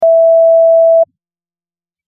Computer Beep 03
Computer_beep_03.mp3